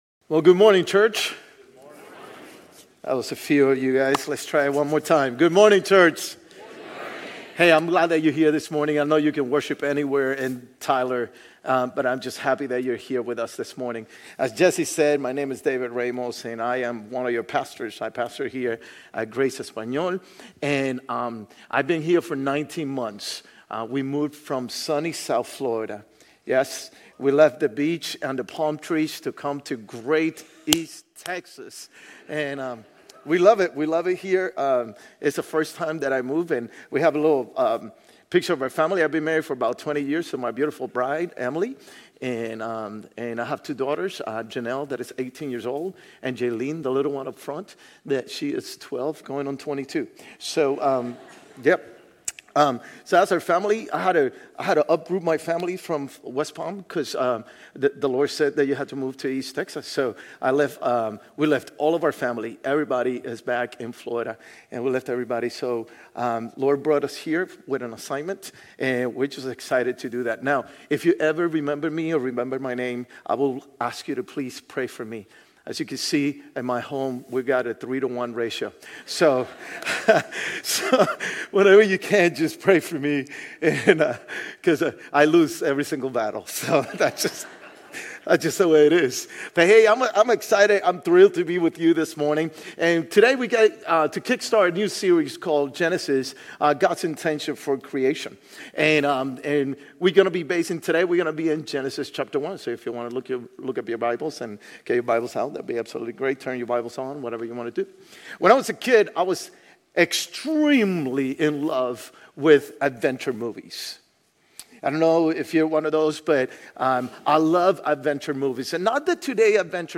Grace Community Church Old Jacksonville Campus Sermons Genesis - Creator Aug 18 2024 | 00:33:14 1x 00:00 / 00:33:14 Subscribe Share RSS Feed Share Link Embed